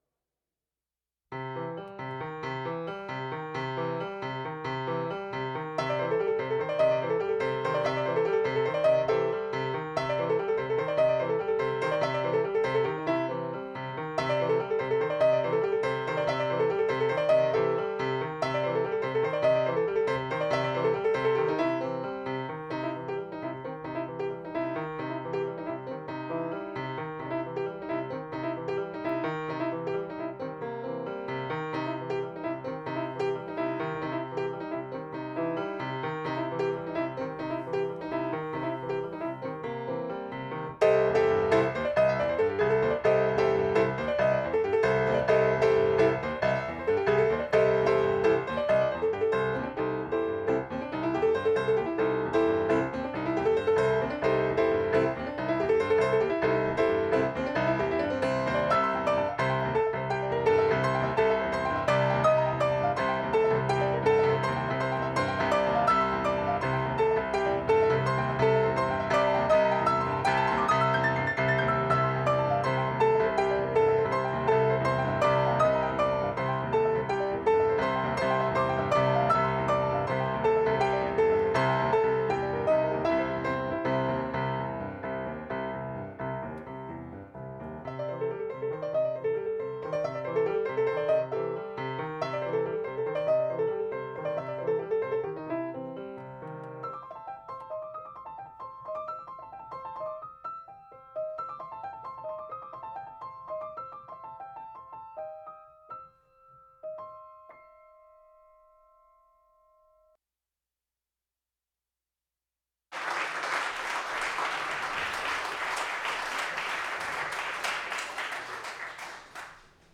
accordion
A livestream will be available free of charge at 8pm on the day of the performance and archived for future viewing.